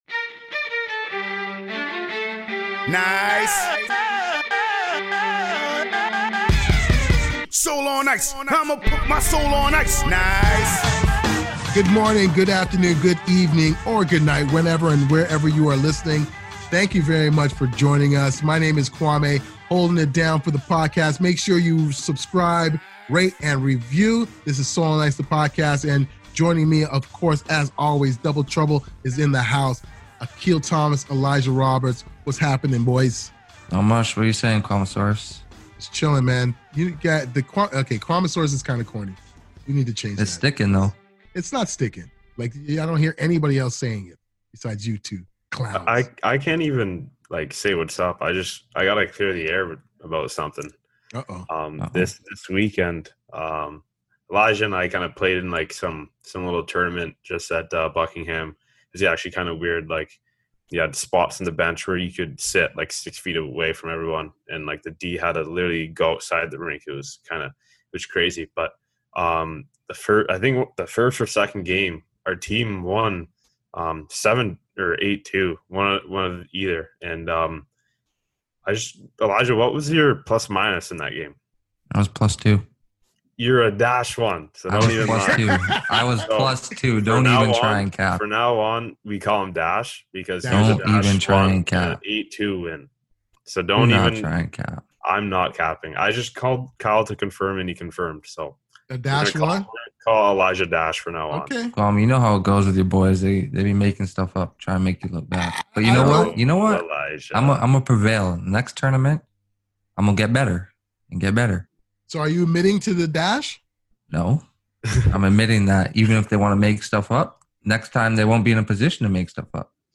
It's an interesting conversation